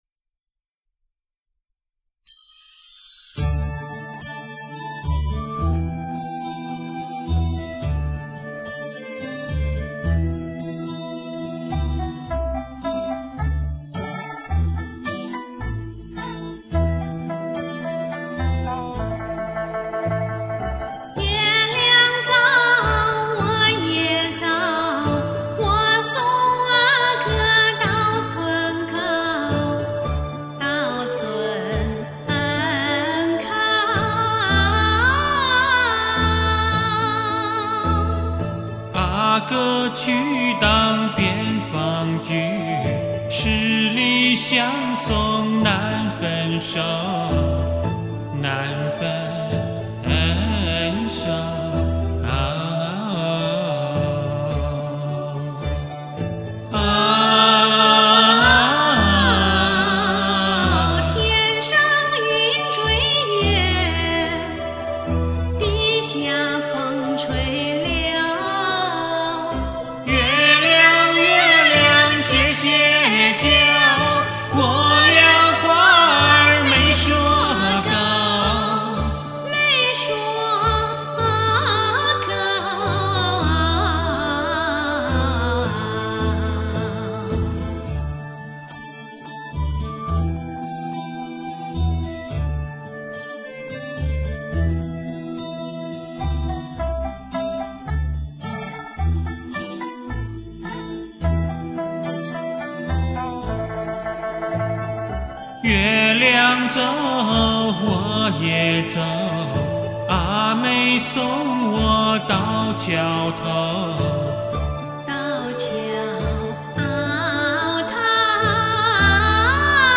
其实不然,这两歌手的音色很好..尤其是句未时,有一股韵味..仔细听听..